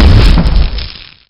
bolt_fire.wav